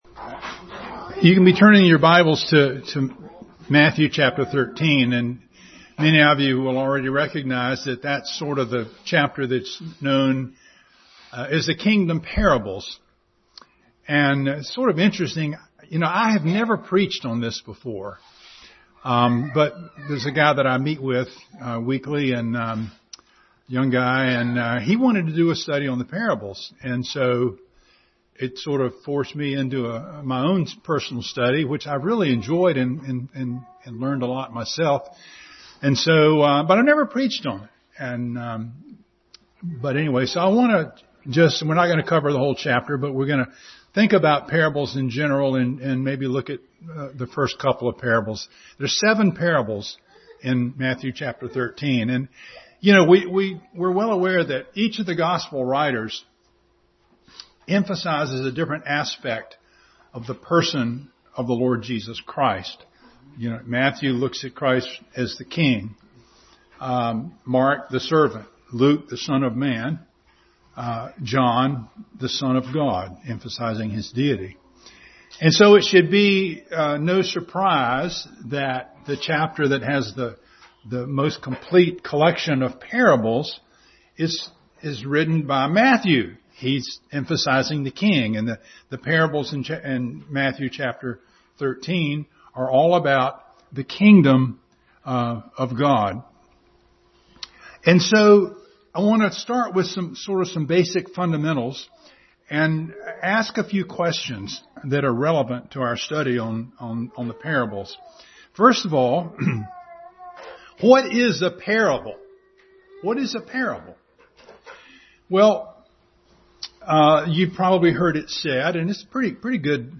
Parables Passage: Matthew 13:1-43, Luke 8:4-18, Mark 12:44, Hebrews 4:2 Service Type: Family Bible Hour